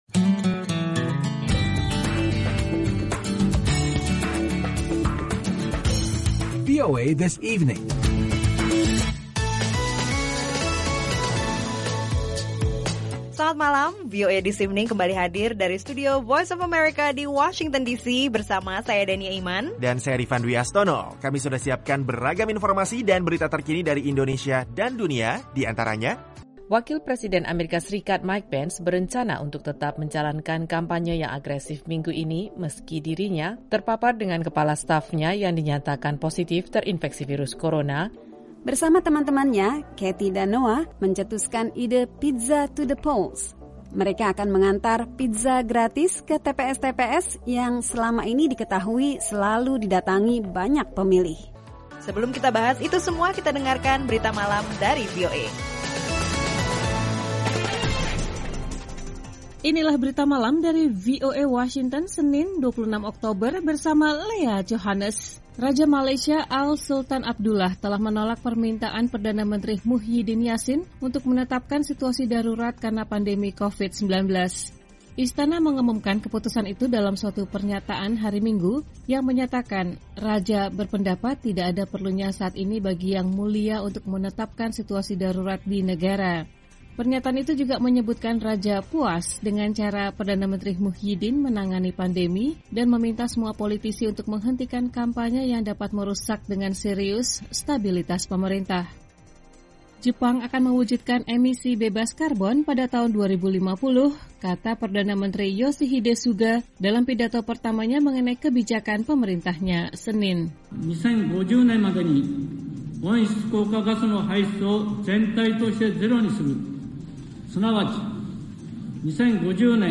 Akhiri kesibukan hari kerja Anda dengan rangkuman berita terpenting dan informasi menarik yang memperkaya wawasan Anda dalam VOA This Evening.